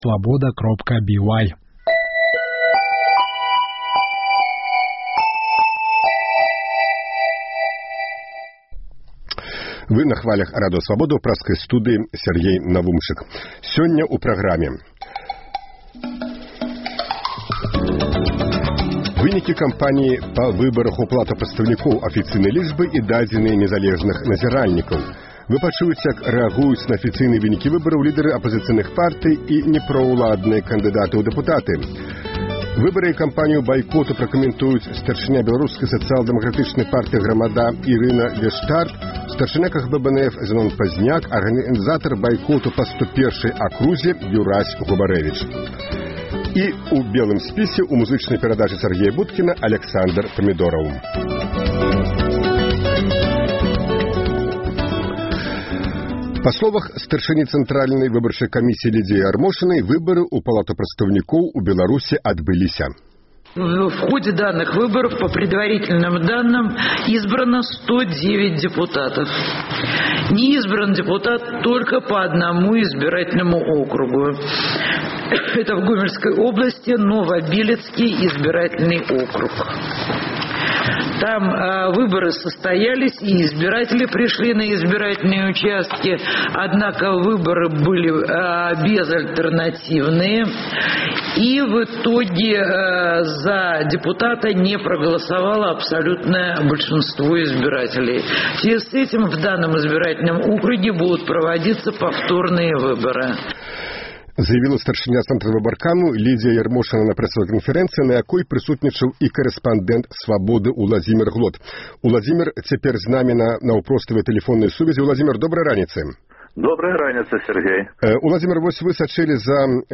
Раніцай 24 верасьня а 7-й гадзіне — жывы эфір Свабоды: рэпартажы з участкаў ад нашых карэспандэнтаў, меркаваньні незалежных назіральнікаў, развагі аналітыкаў.